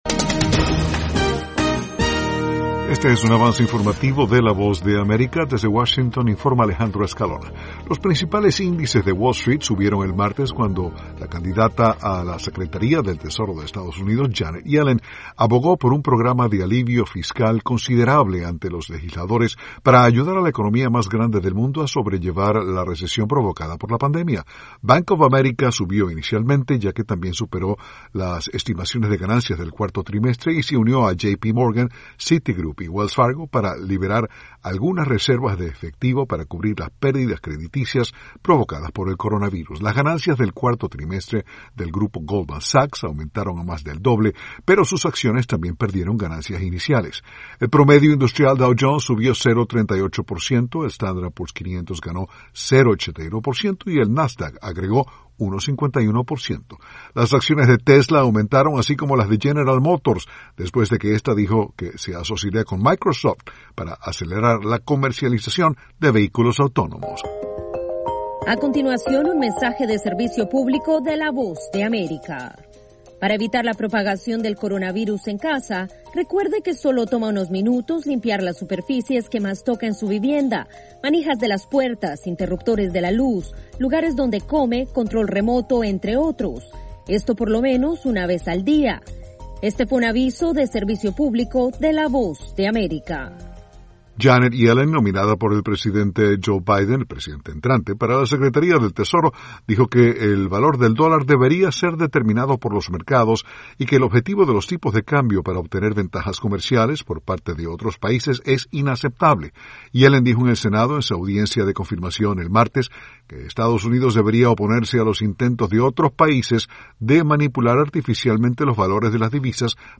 Avance Informativo 6:00pm